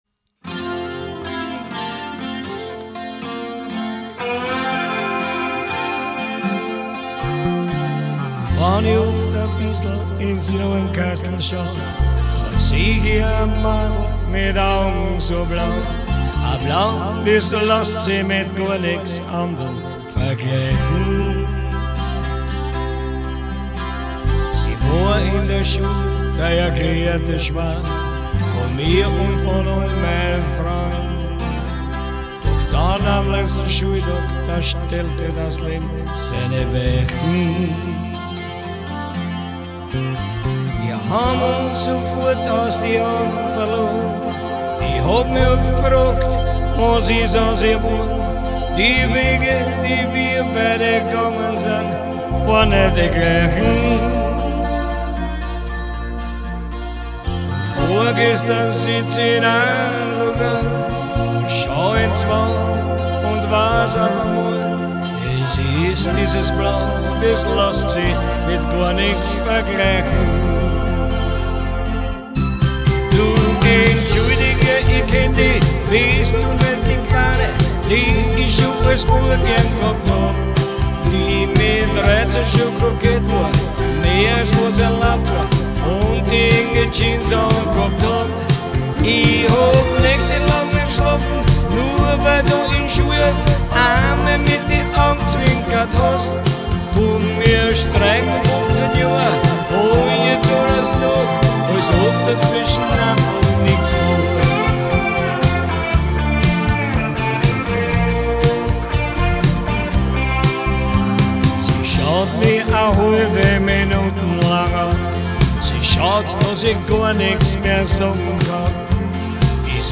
Schmusesongs